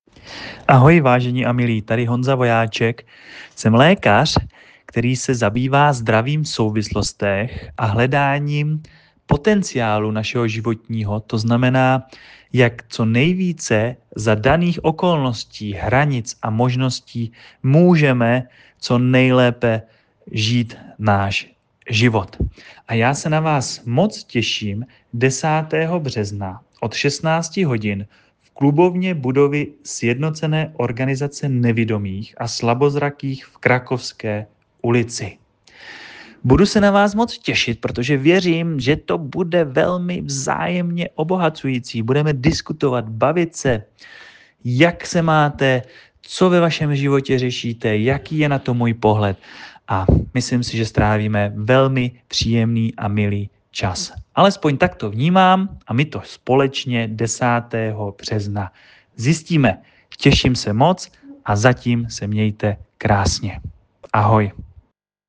pozvánka.mp3